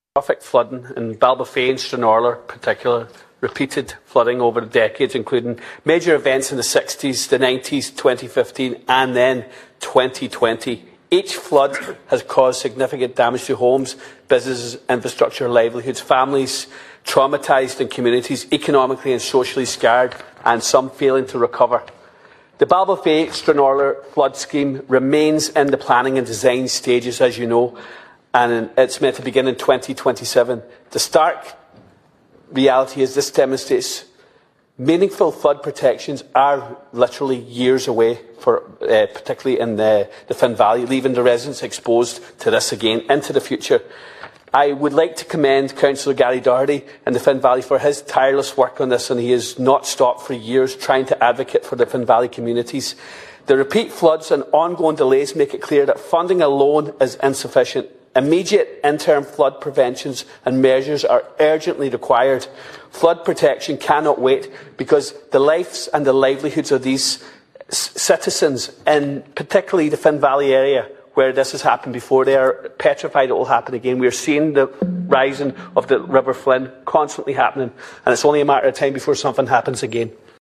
Deputy Charles Ward raised the issue in the chamber yesterday, with particular focus on the Twin Towns.